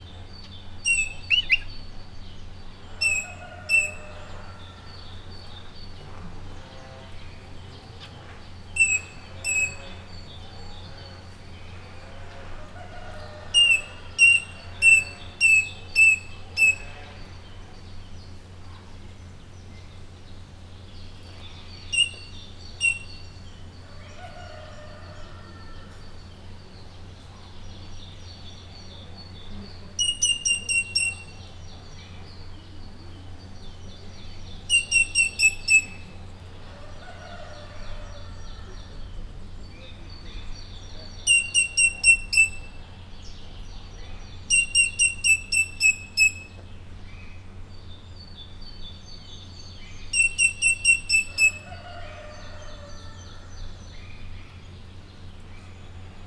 rozellaeskakasis_miskolczoo0056.WAV